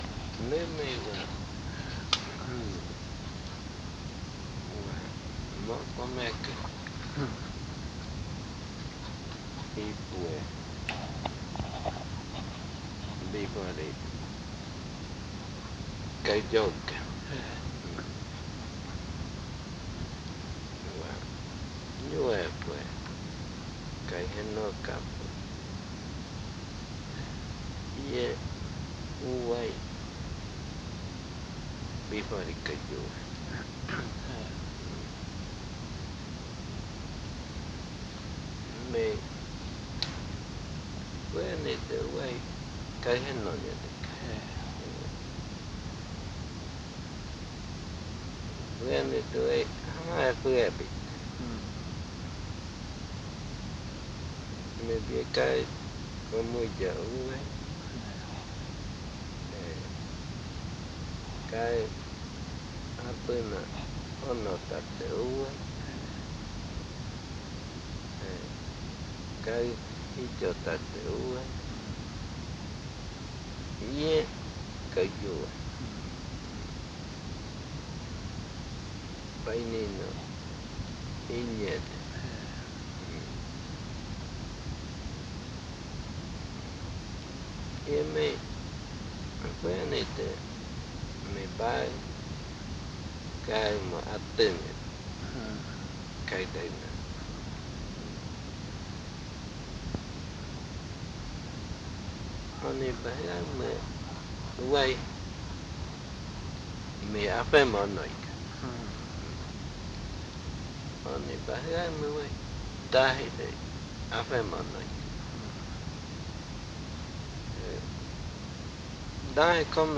Palabra de mambeadero hablando de las cosas buenas que hay buscar, evitando hablar de lo que son problemas.